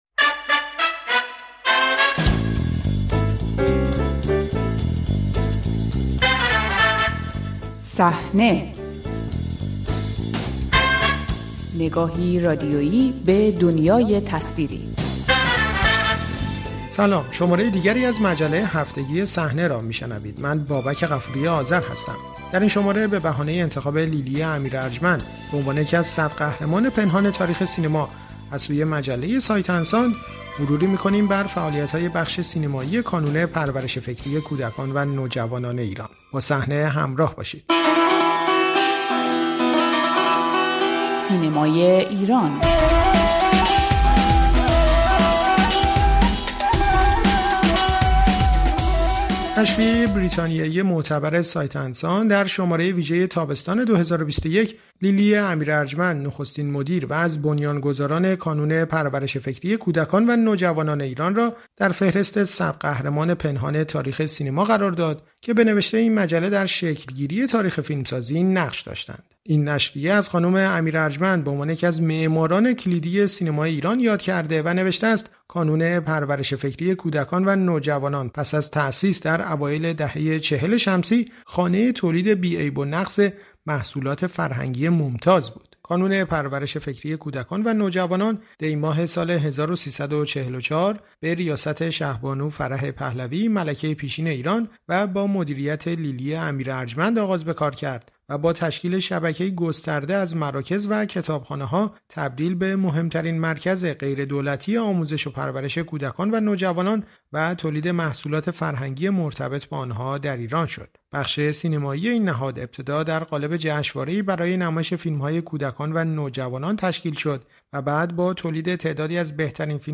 محمدعلی طالبی در مصاحبه با رادیو فردا از خاطرات نوجوانی خود از دوران آموزش فیلمسازی در کانون پرورش فکری کودکان و نوجوانان و نقش تاریخی این کانون در تربیت نسلی از هنرمندان می‌گوید.